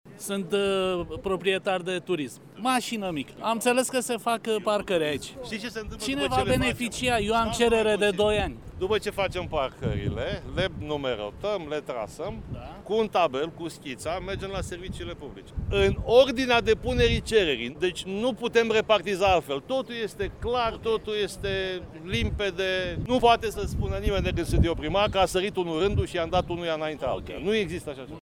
Declarația a fost făcută astăzi de primarul Vergil Chițac, la o întâlnire cu cetățenii domiciliați în zona adicentă străzii Corbului.
Edilul-șef a precizat că, pe măsură ce sunt amenajate noi parcări rezidențiale, acestea sunt alocate în funcție de vechimea solicitărilor depuse la Primărie: